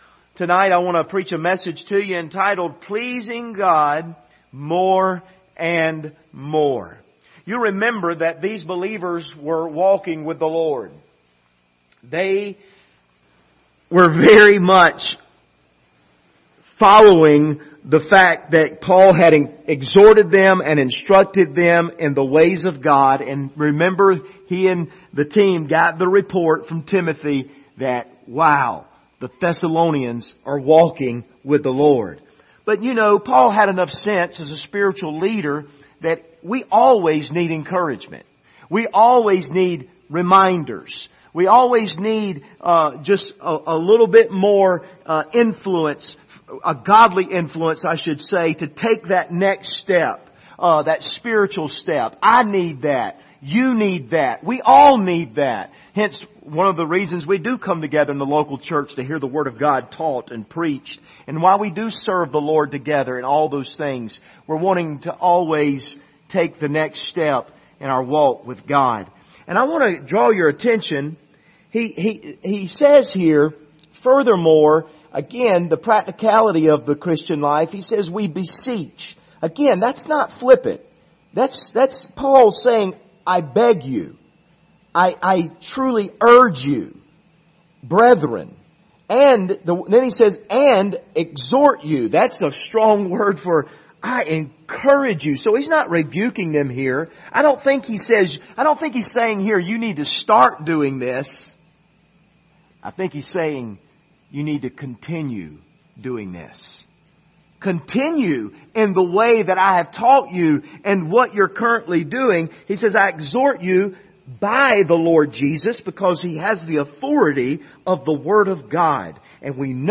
1 Thessalonians 4:1 Service Type: Sunday Evening « Jesus